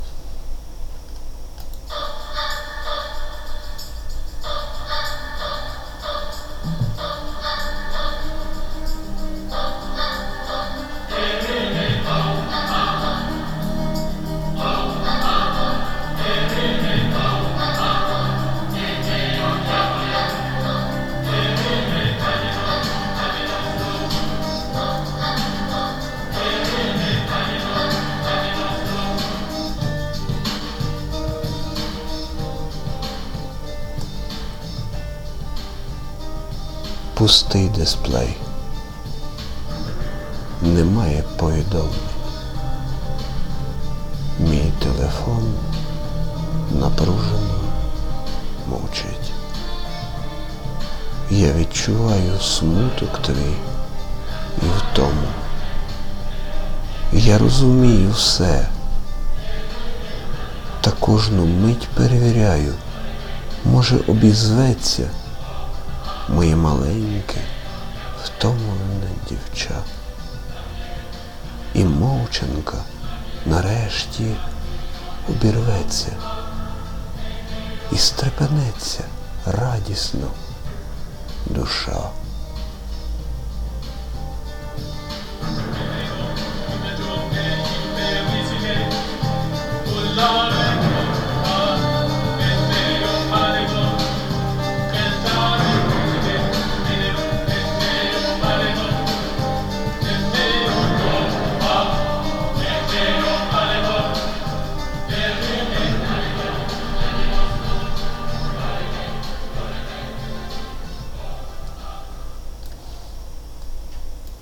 Рубрика: Поезія, Лірика
слухаю... тепер вірш забарвлений ще більш емоційно, насичуються відтінки суму, надії...
тепер можна почути голос автора smile